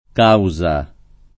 [ k # u @ a ]